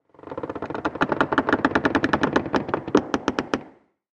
Звук скрипа избушки Бабы-Яги повернись ко мне передом а к Ивану задом